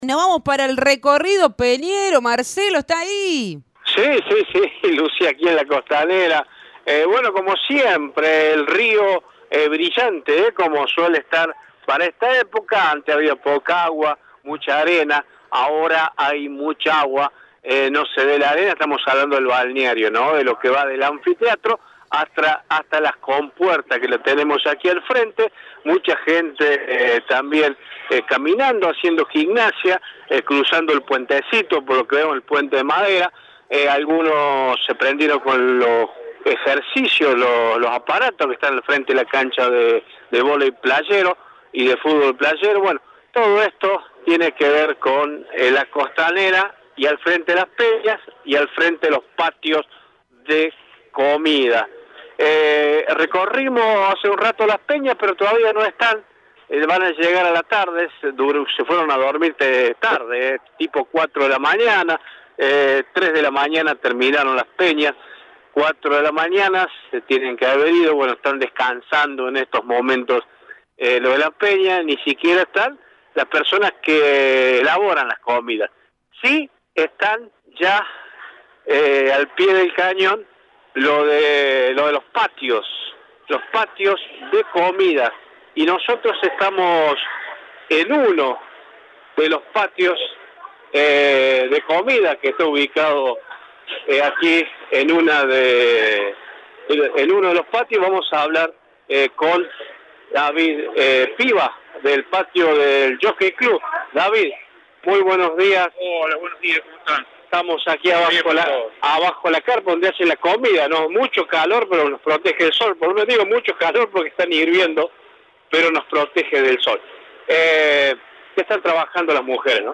El Recorrido Peñero cumplió su primer fin de semana. El móvil de la radio estuvo visitando los patios de comidas para que realicen un balance de las primeras jornadas.